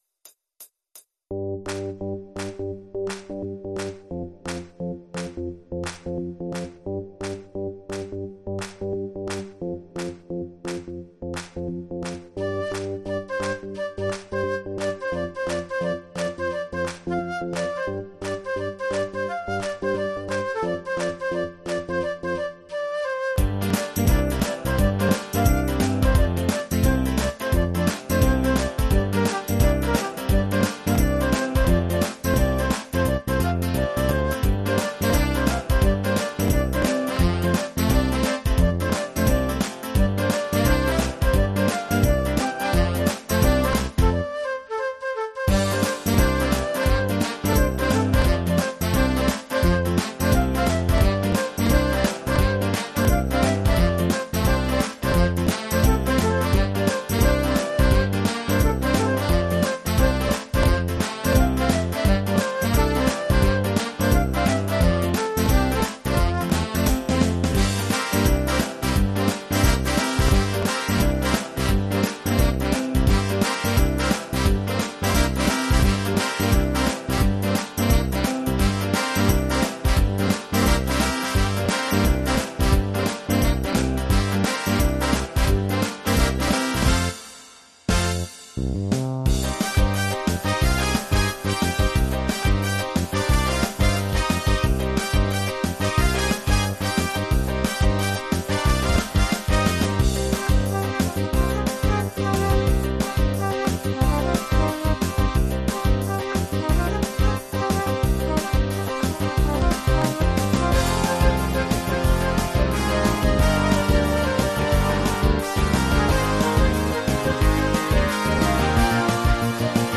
multi-track instrumental version